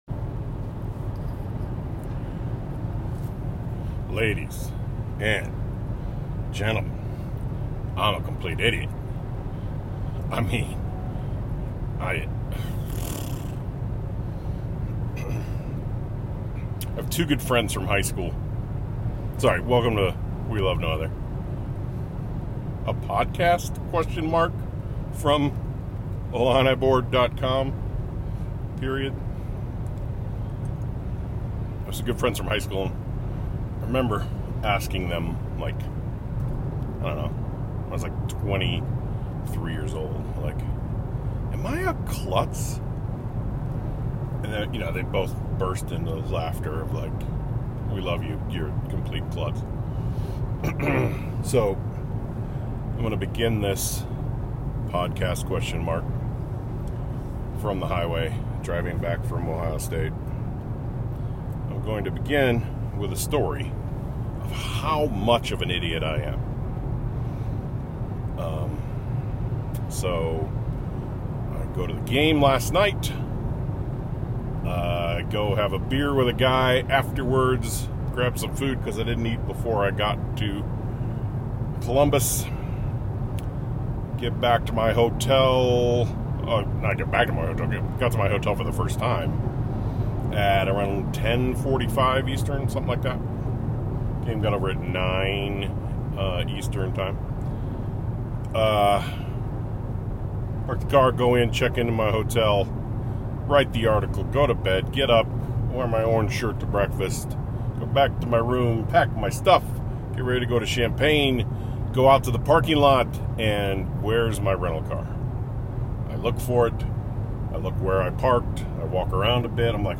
I asked for questions on Twitter and then pressed record as I was driving from Columbus to Champaign in my (totally not stolen) rental car.